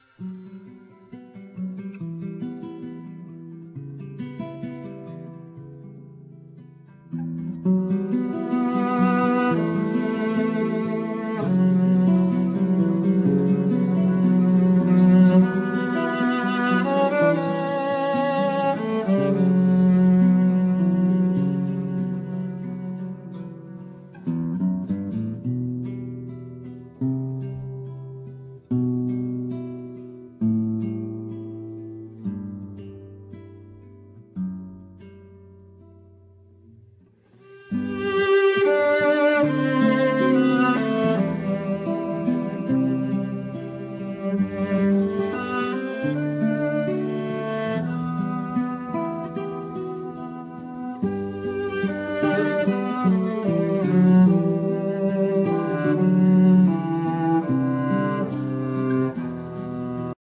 Guitar,Highland pipes,Bodhran
Cello
Djemba,Cajon peruano,Caja
Vocals
Recitado
Pandereta